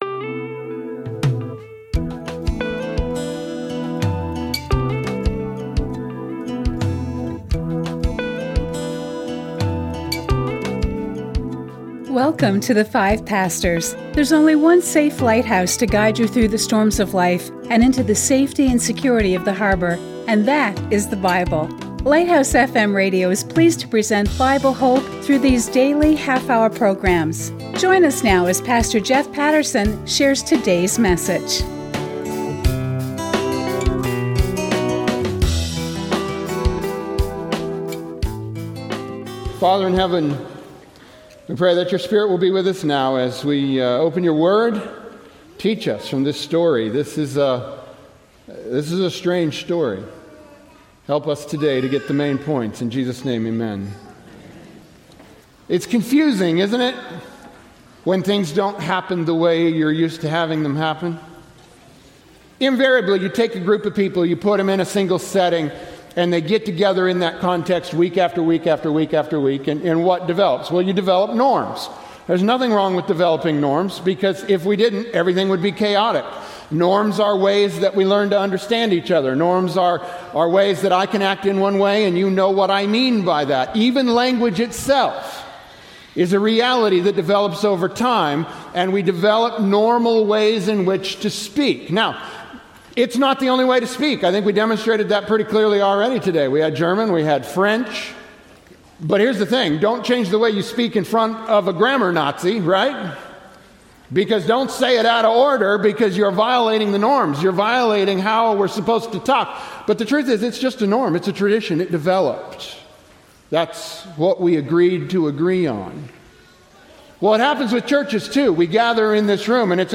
Inspiring sermons presented by 5 pastors